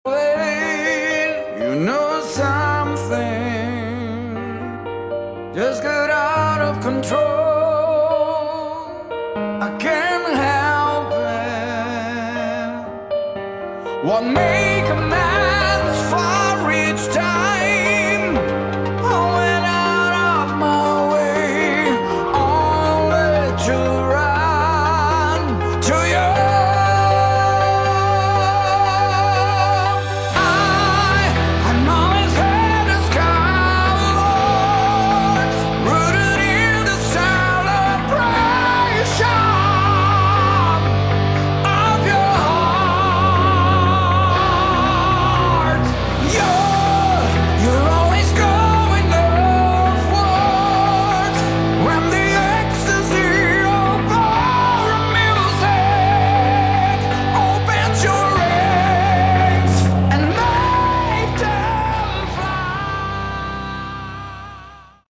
Category: Melodic Metal
vocals
guitars
bass, keyboards, backing vocals
drums